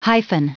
Prononciation du mot hyphen en anglais (fichier audio)
Prononciation du mot : hyphen